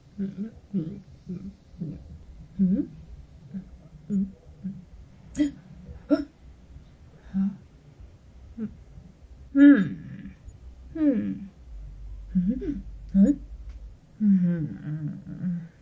描述：语音
标签： 女性 声带 声音
声道立体声